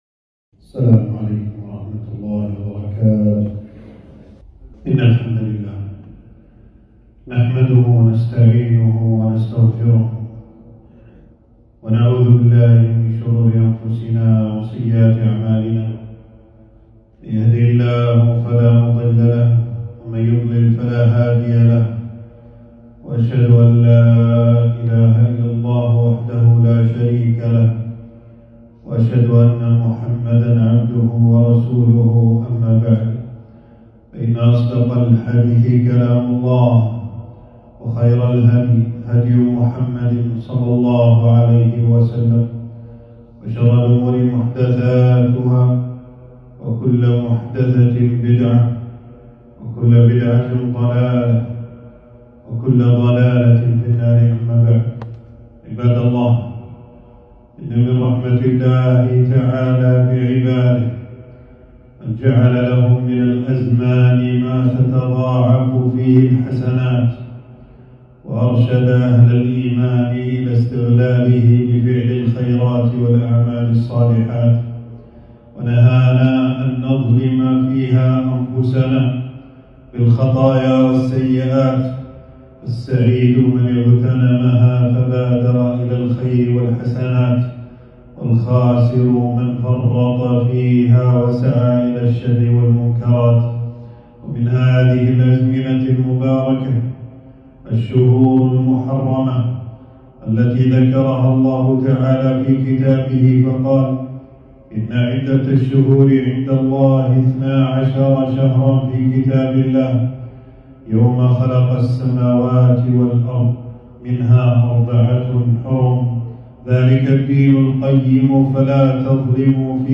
خطبة - شهر الله المحرم بين الاتباع والابتداع